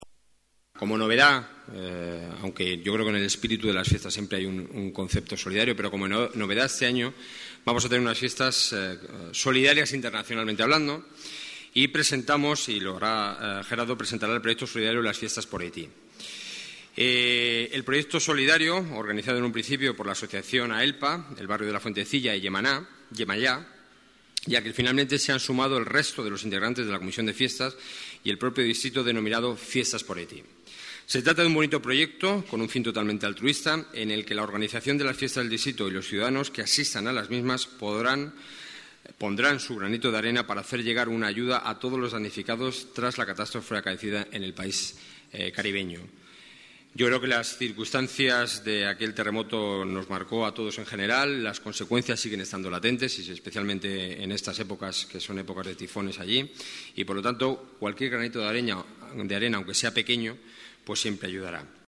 Nueva ventana:José Enrique Núñez, concejal presidente de la Junta Municipal de Centro, habla sobre las fiestas del distrito